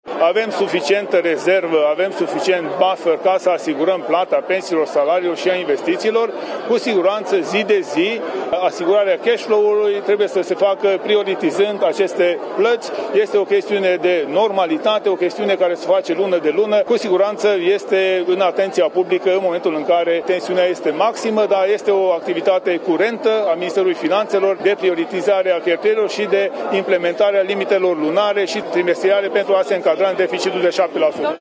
Ministrul Finanțelor, Tanczos Barna: „Avem suficientă rezervă ca să asigurăm plata pensiilor, salariilor și a investițiilor”